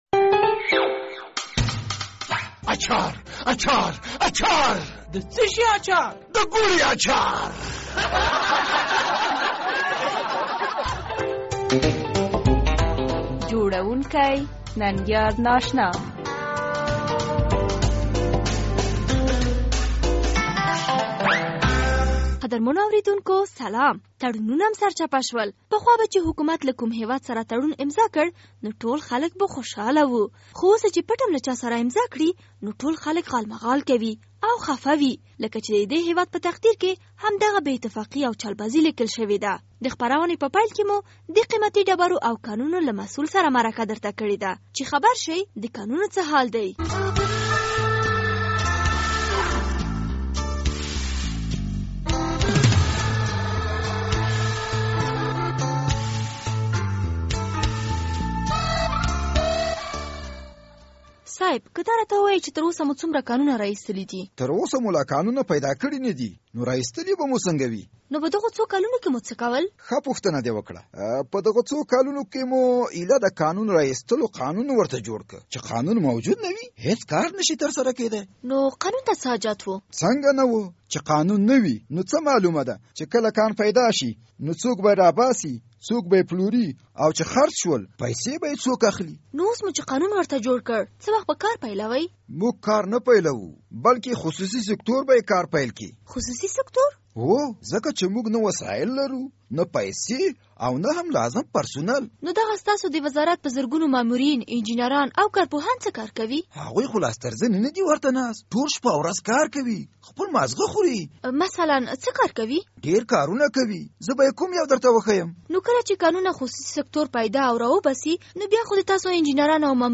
د ګوړې اچارپه دې خپرونه کې به لومړی د کانونو له نامسول سره زموږ کړې مرکه واورئ.